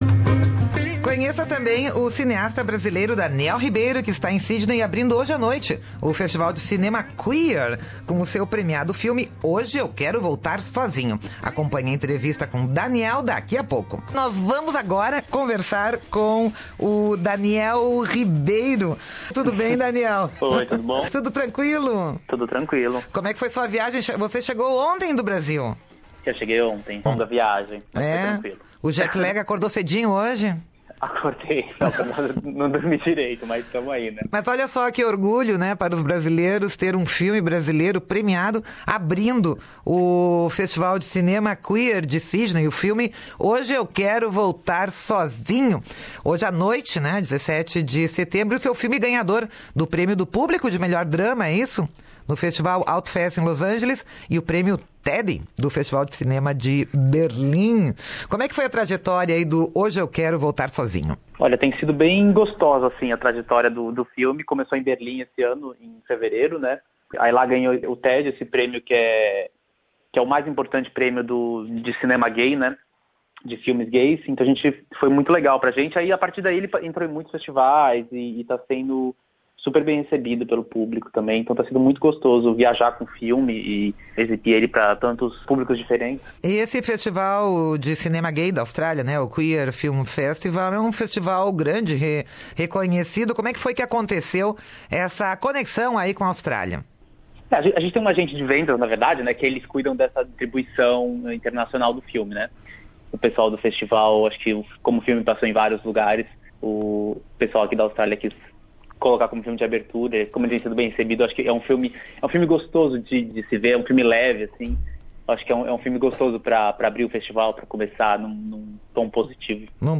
nesta entrevista à Rádio SBS.